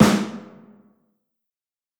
SNARE_BOMBS.wav